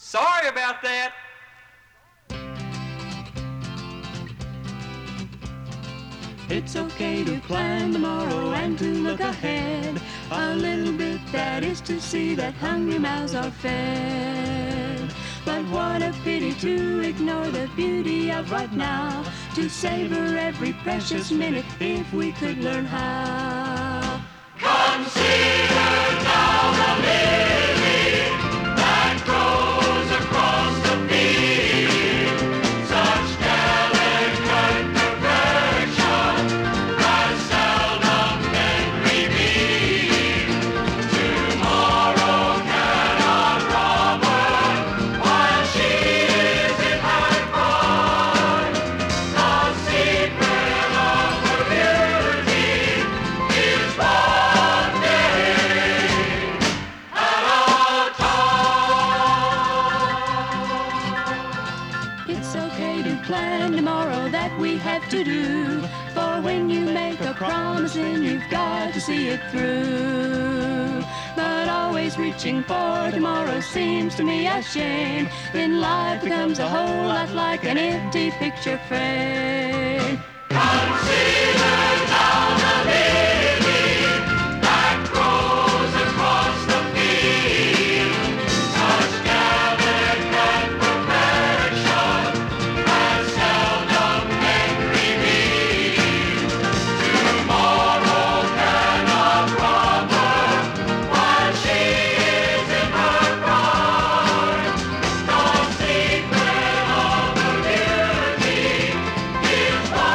＊稀に軽いチリ/パチ・ノイズ。